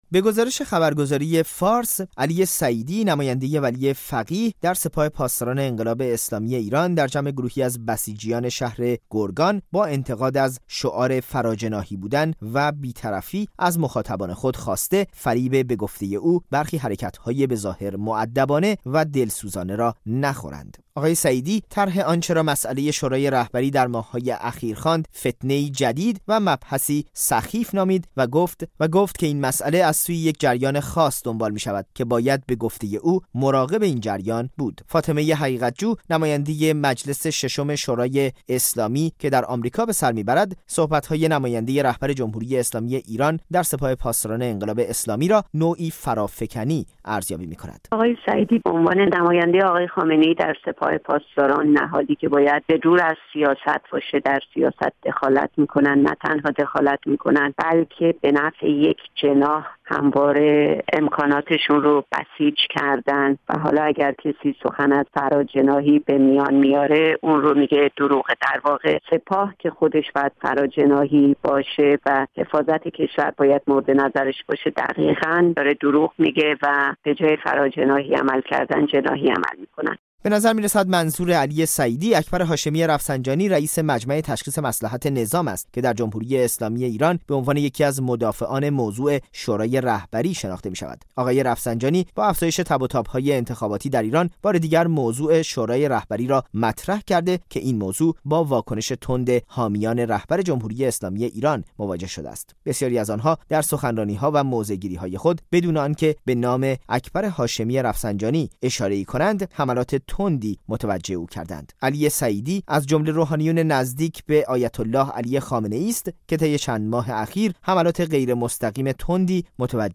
گزار ش